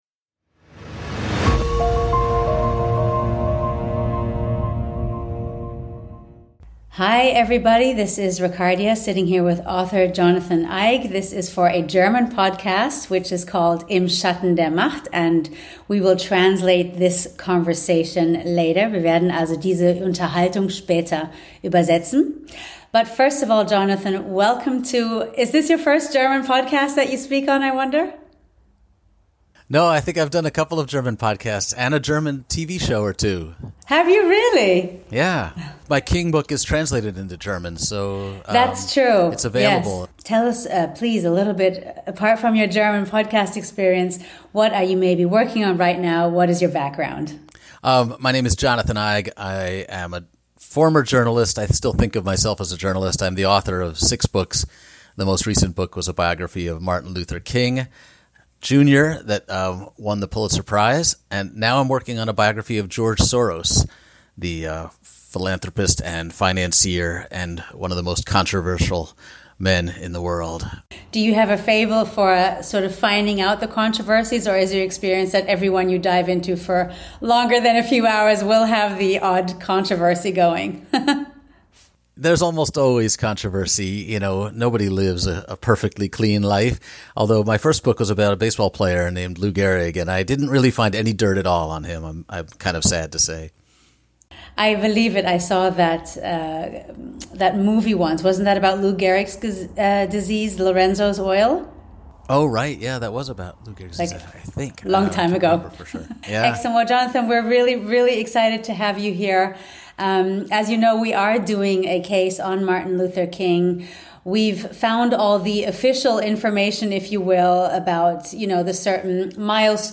Dafür haben wir Jonathan Eig angerufen.